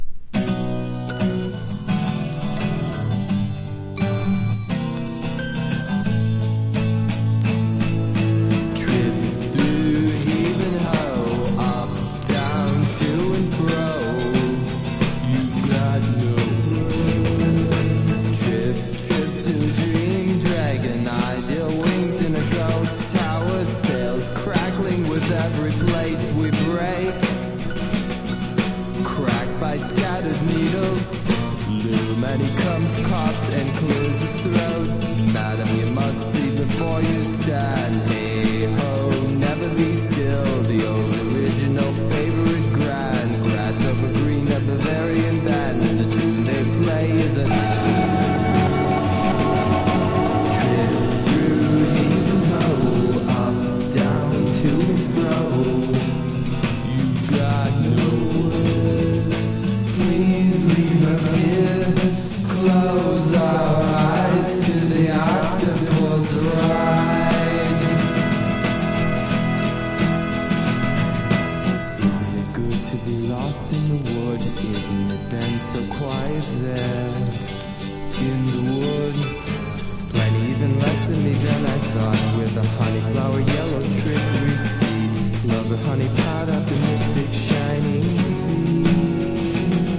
サイケ～アシッド感の強い内容で、彼の影響を受けた当時の若手バンドが見事カヴァー。